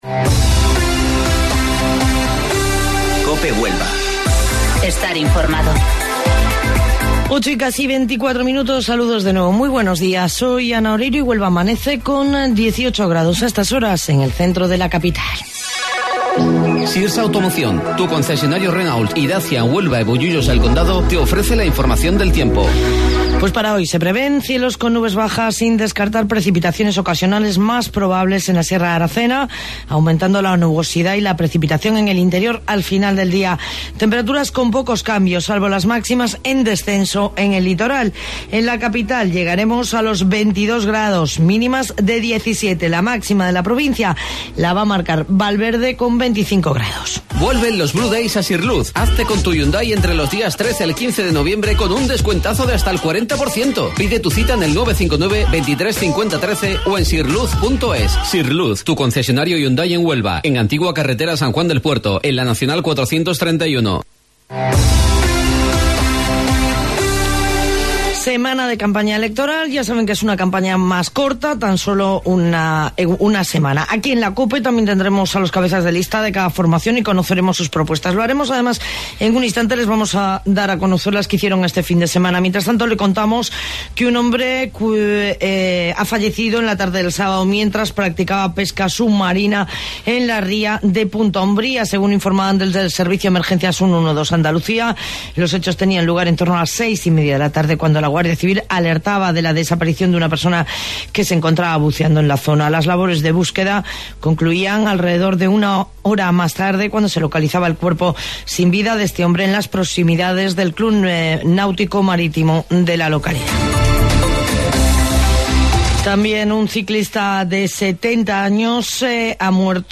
AUDIO: Informativo Local 08:25 del 4 de Noviembre